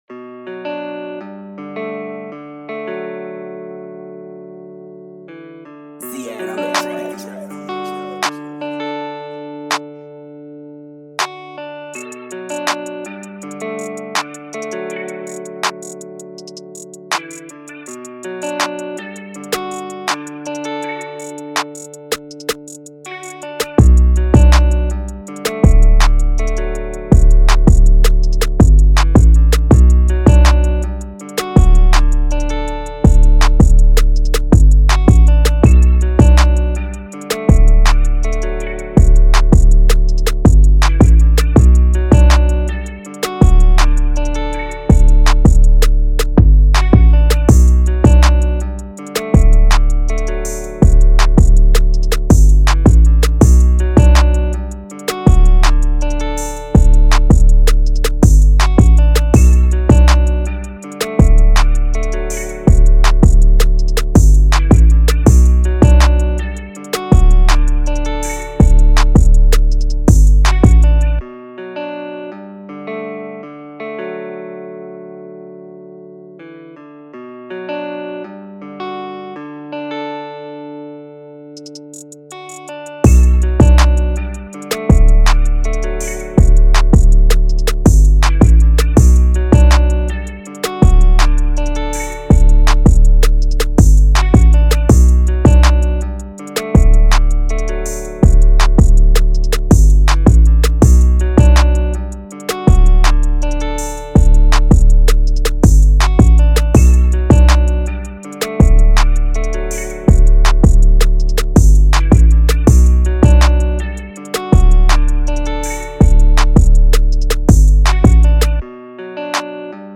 2024 in Hip-Hop Instrumentals